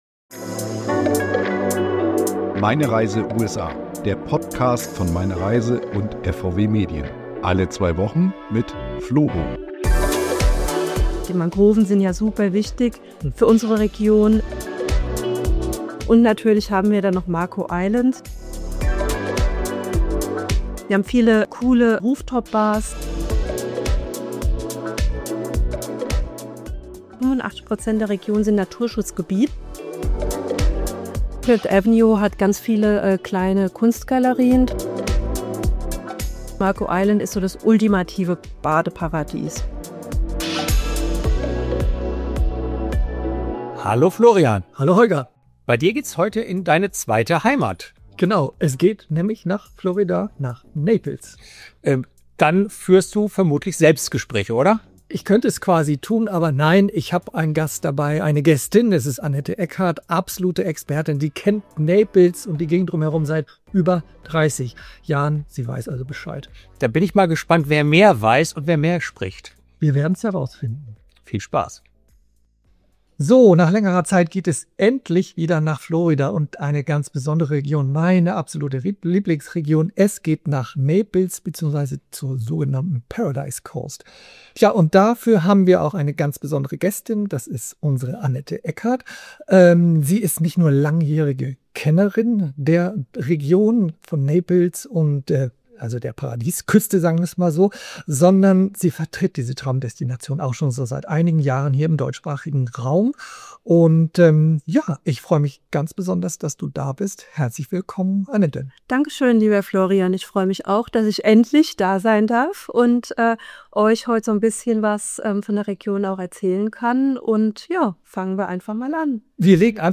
Übrigens: Die Florida-Kennerin und der Moderator haben denselben Lieblingsstrand.